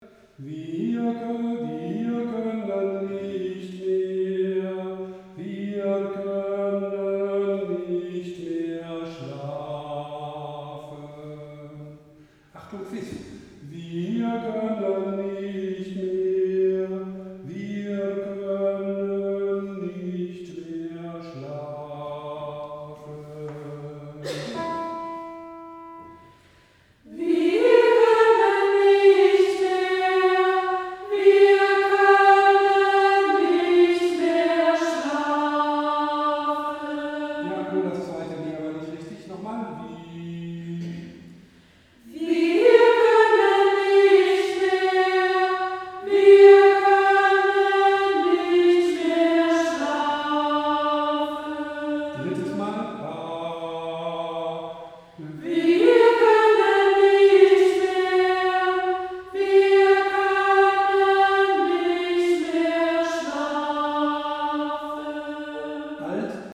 Takt 35 - 42 | Einzelstimmen
Gott in uns! | T 35 | Sopran 4